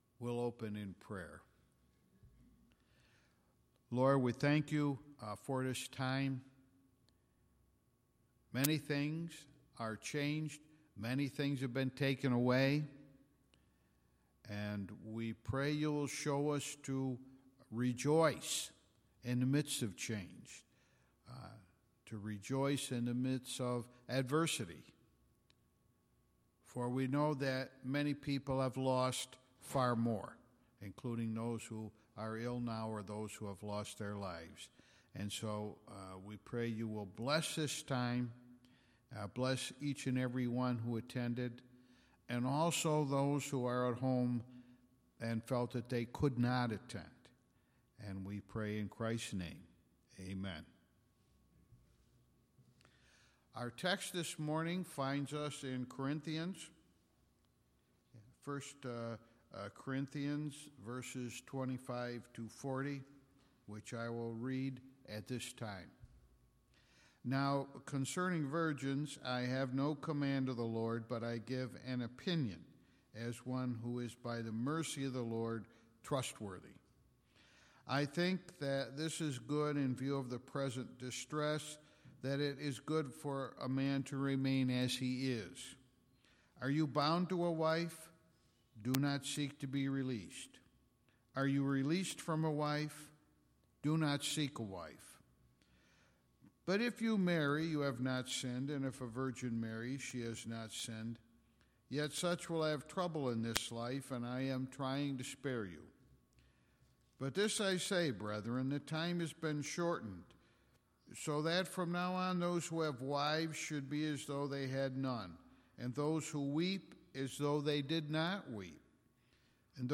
Sermons List